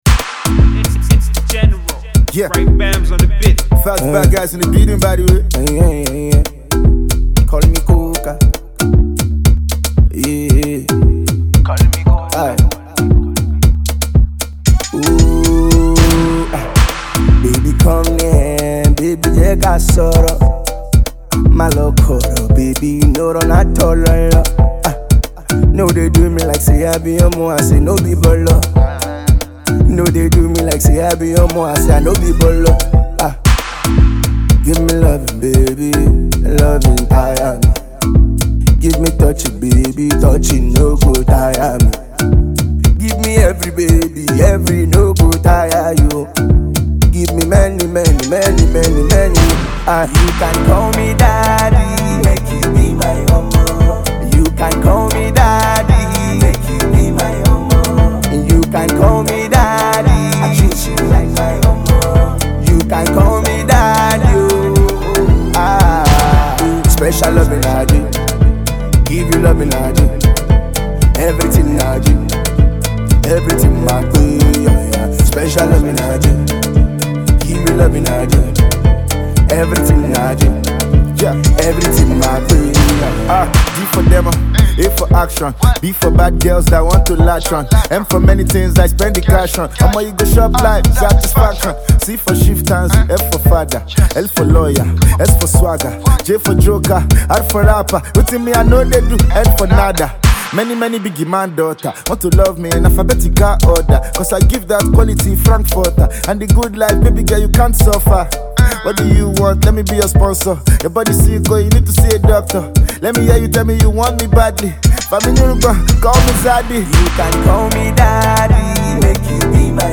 melodious sing along